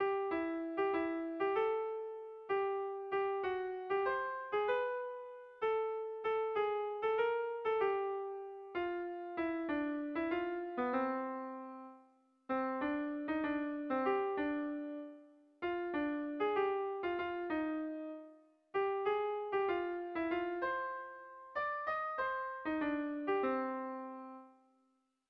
Zortziko txikia (hg) / Lau puntuko txikia (ip)